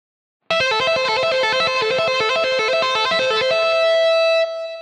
Гитарное упражнение 3
Аудио (100 УВМ)